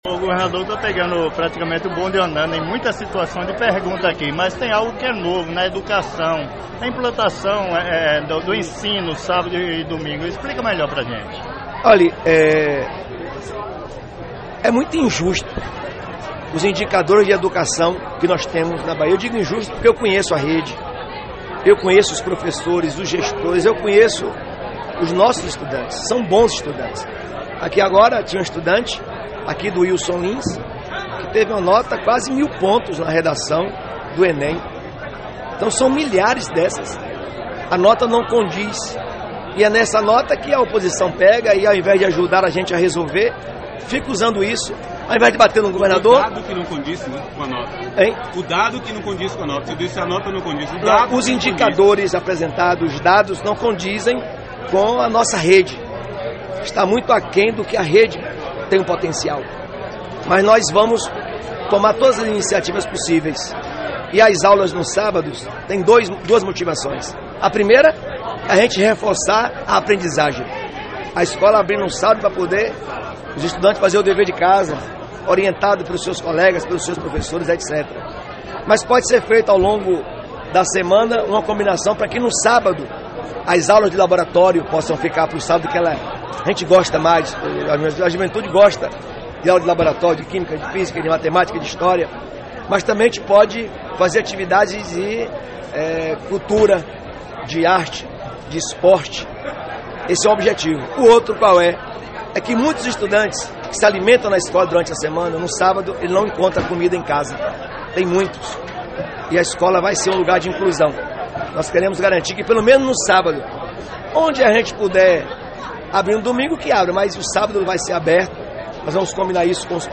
Após o ato, aconteceu uma entrevista coletiva com o governador e o Calila Notícias abordou mais uma vez o assunto, inclusive cita os dois principais motivos da abertura da escolas – Ouça